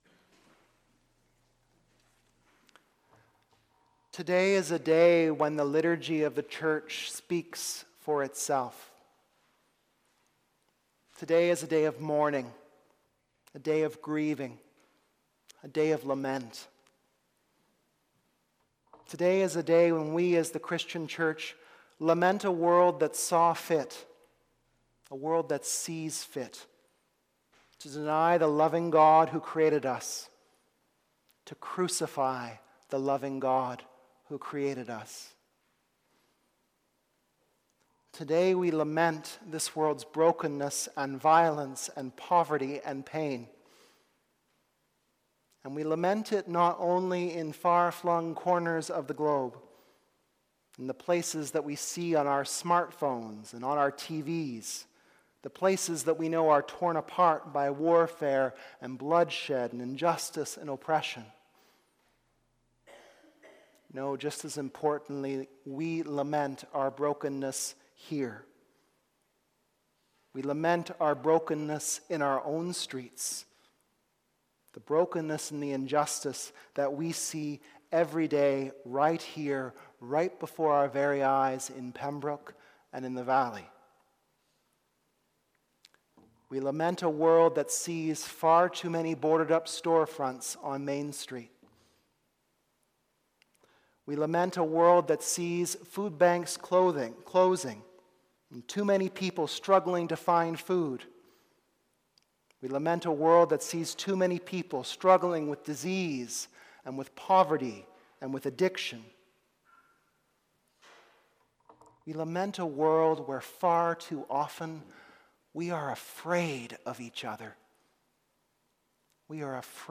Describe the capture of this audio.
Also preached at St. Patrick's, Lower Stafford.